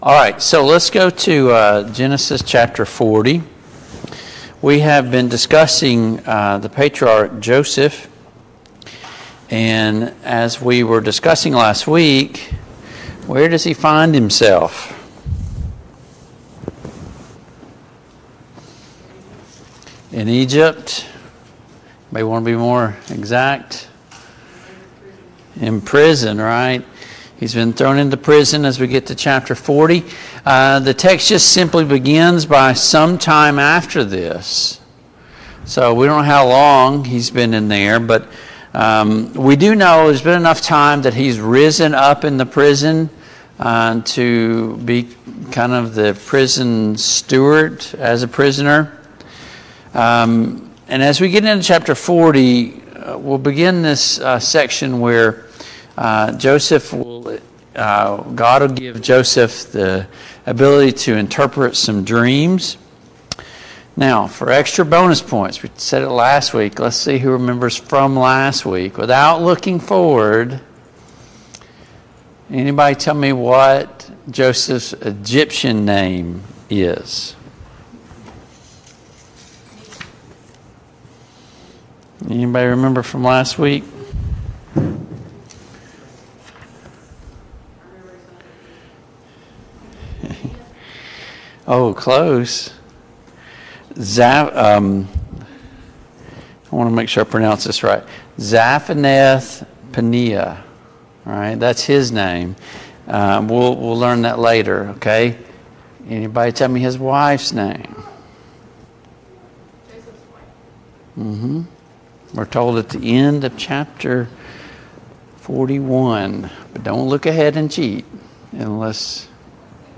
Study of Genesis Passage: Genesis 40-41 Service Type: Family Bible Hour Topics